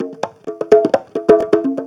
Conga Loop 128 BPM (12).wav